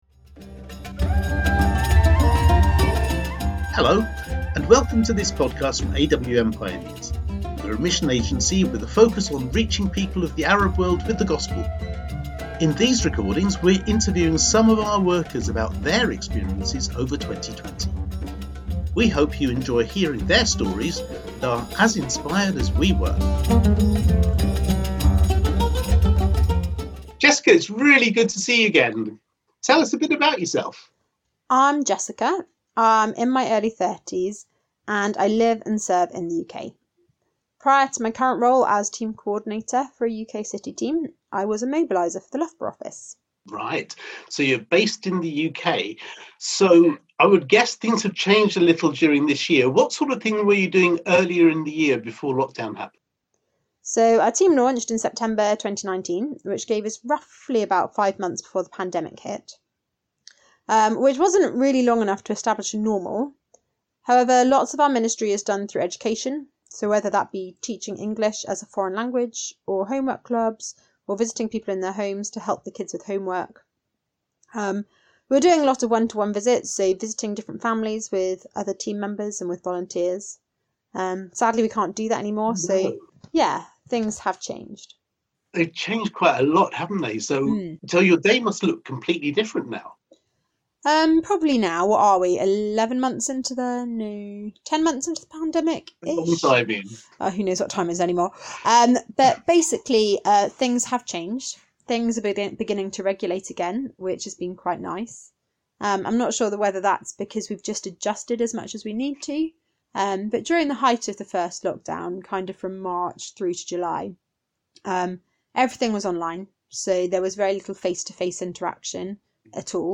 In these short podcasts, we've interviewed workers about their experiences of 2020.